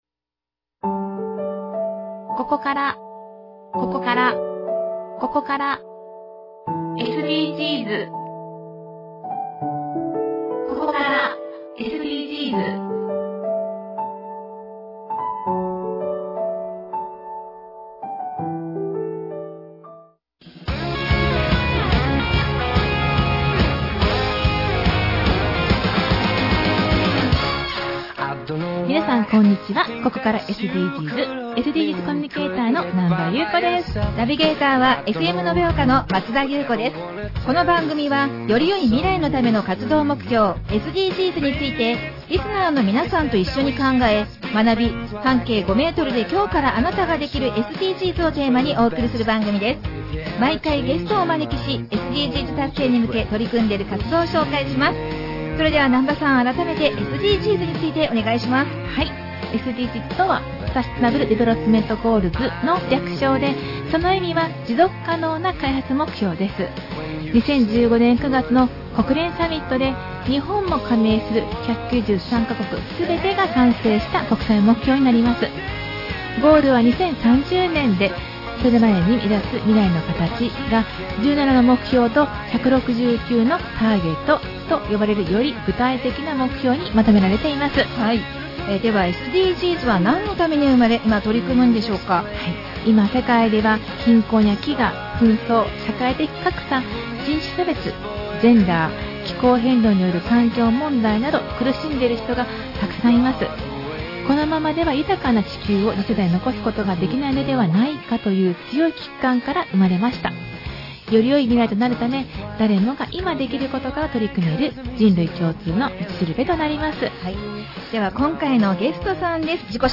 特別番組